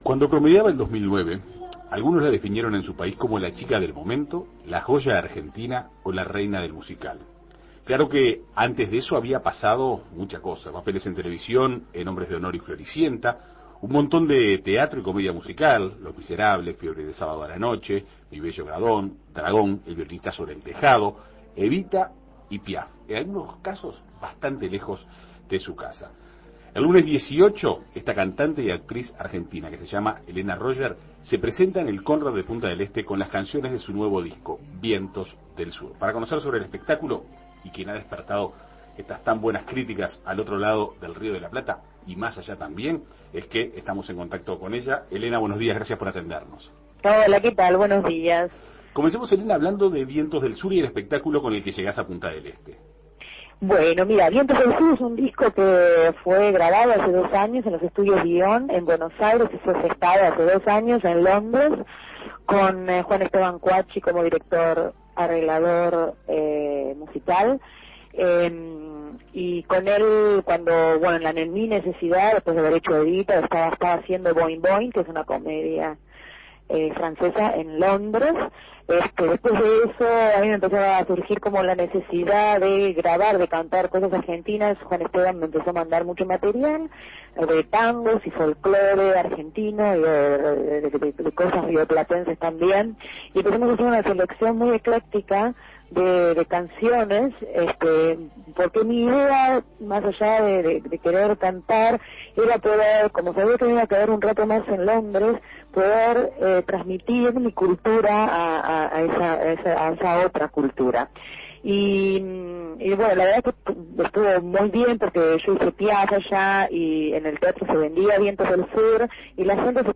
Este lunes, la cantante y actriz argentina Elena Roger se presentará en el Conrad de Punta del Este con las canciones de su último disco, Vientos del Sur. Para conocer detalles del espectáculo, En Perspectiva Segunda Mañana dialogó con la artista.